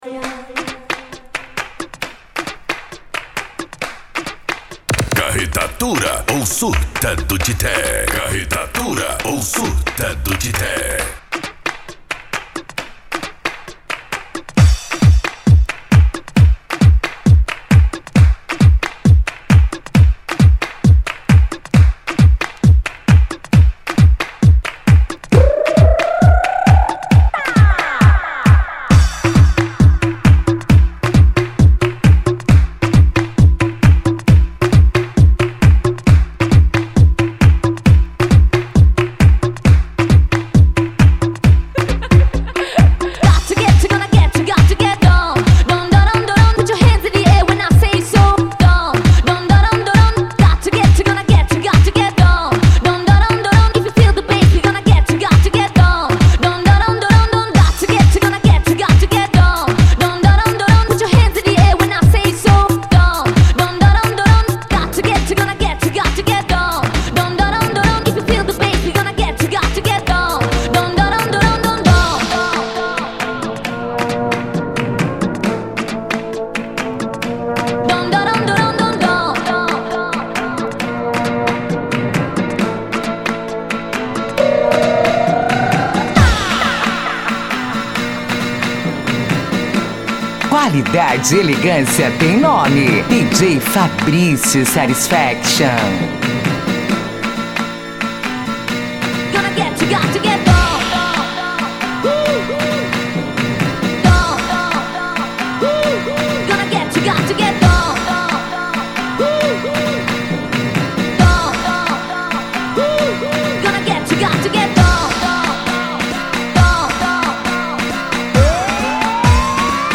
Euro Dance
Funk
Sets Mixados